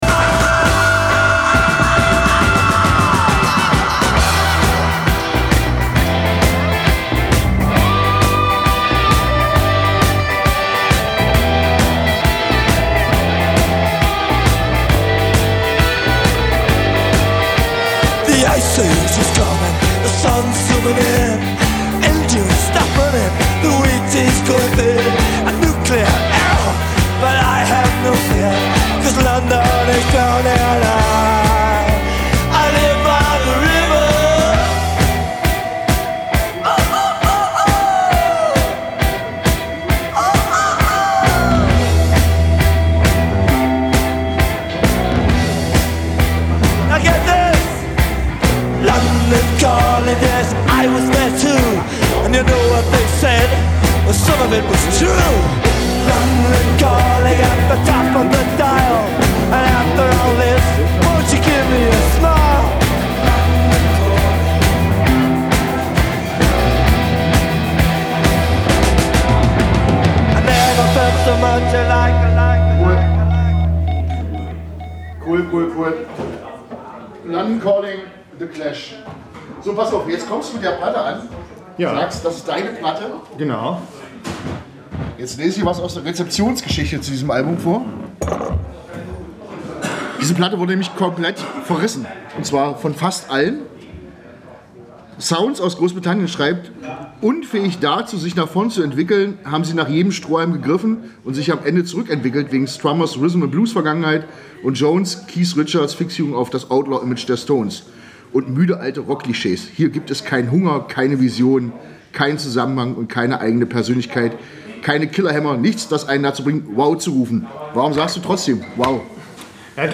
Ausschnitt aus dem Talk